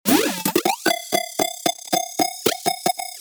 Since Serum is a staple in nearly every producer’s setup, we crafted a collection of wild, unique presets designed to push your sound further.
SERUM_ SYN – Wallop
SERUM_-SYN-Wallop.mp3